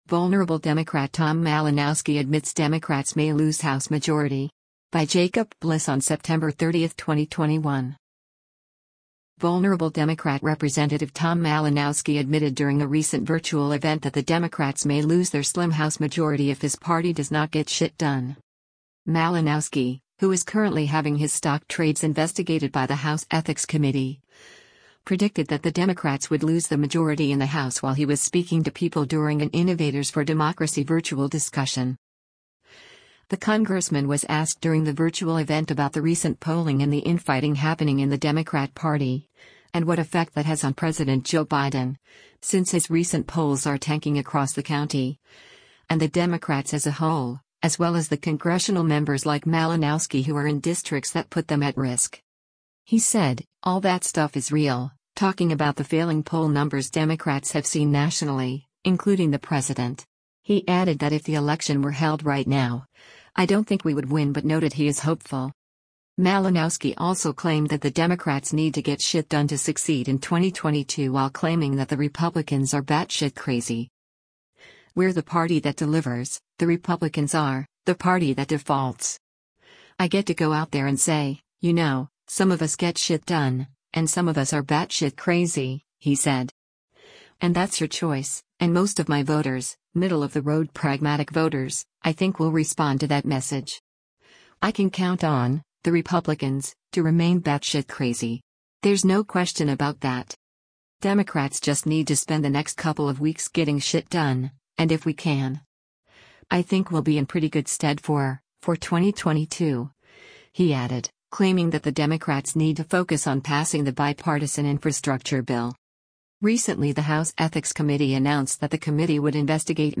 Malinowski — who is currently having his stock trades investigated by the House Ethics Committee — predicted that the Democrats would lose the majority in the House while he was speaking to people during an “Innovators For Democracy Virtual Discussion.”